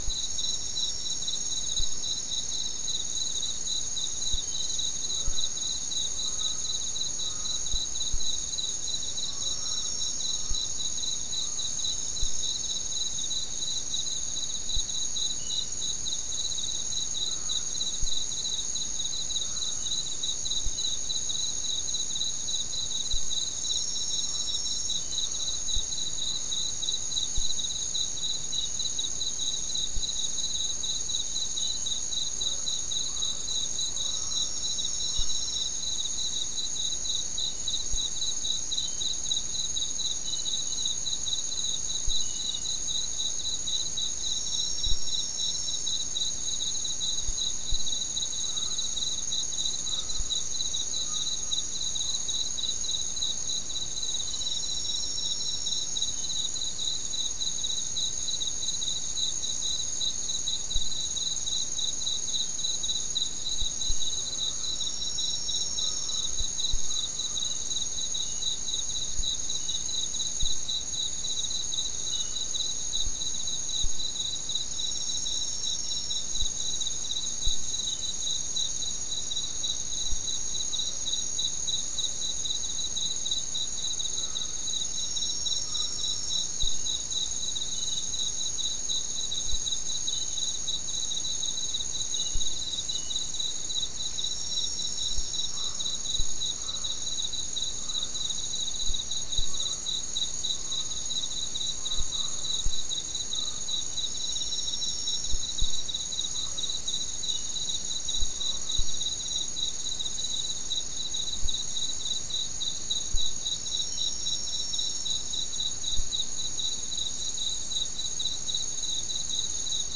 Non-specimen recording: Soundscape Recording Location: South America: Guyana: Mill Site: 3